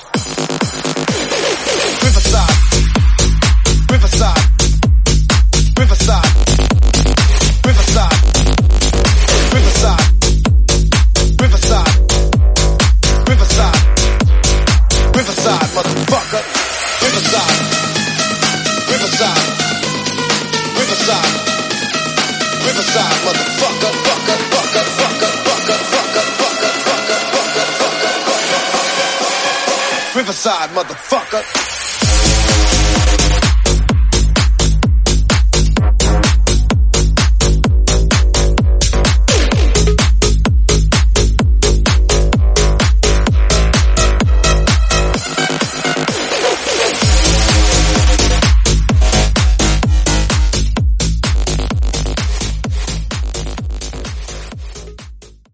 Club Remix. Dj Intro Outro
Genres: 2000's , RE-DRUM , TOP40
Clean BPM: 128 Time